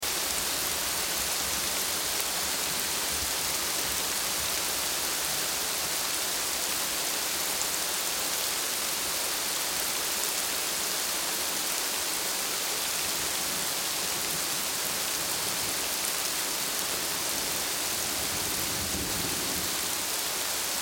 جلوه های صوتی
دانلود صدای جنگل 8 از ساعد نیوز با لینک مستقیم و کیفیت بالا